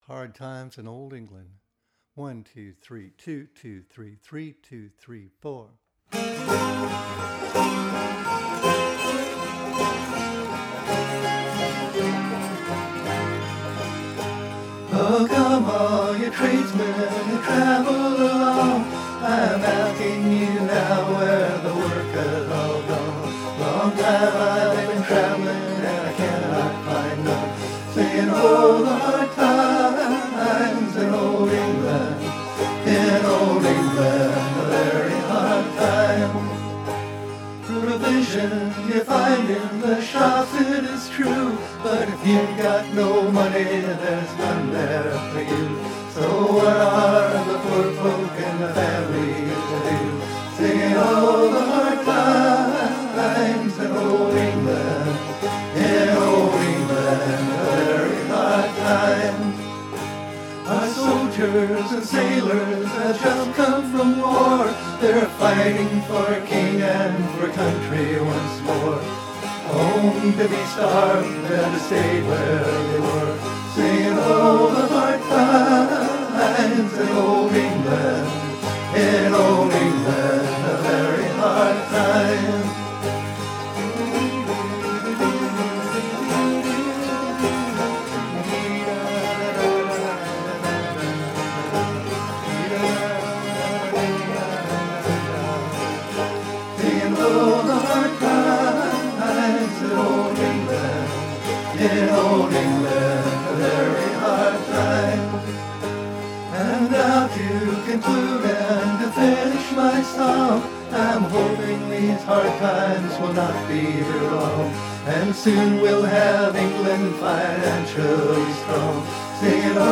The earliest broadside of this ballad came out before 1820, following the Napoleonic Wars.